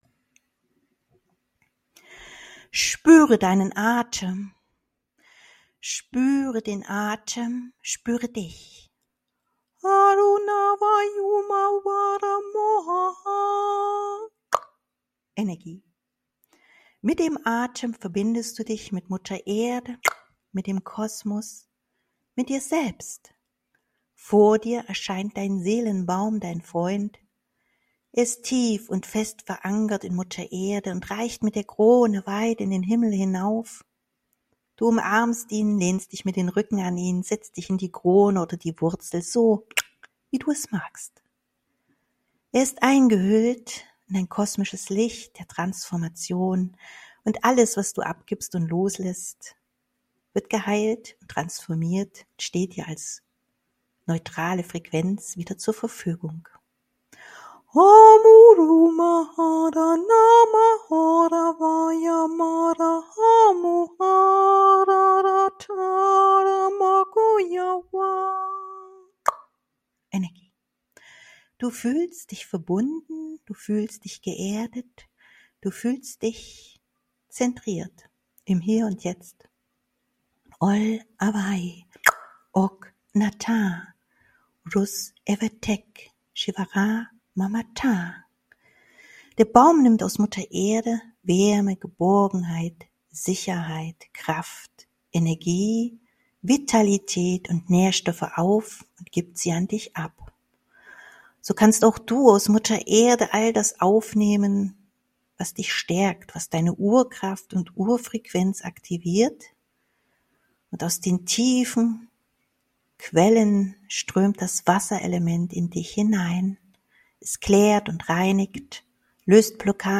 Sommersonnenwende - Meditation